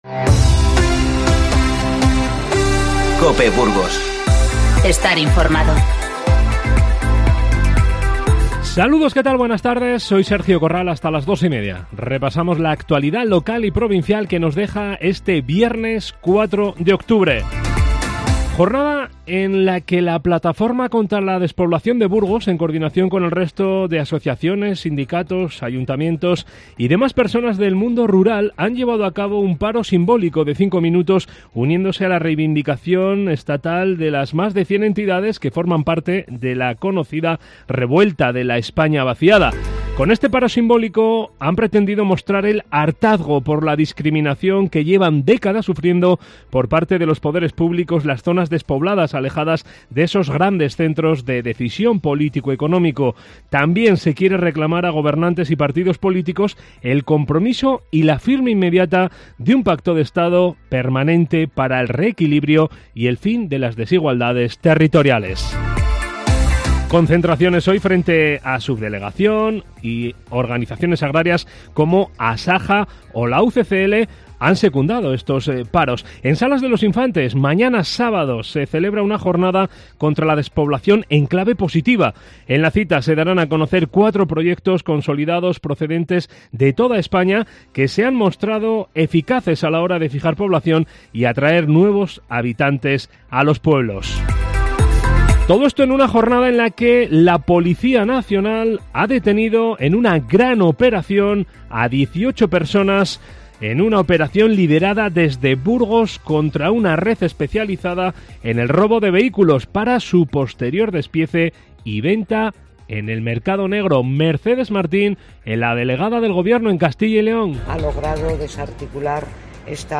INFORMATIVO Mediodía 4-10-19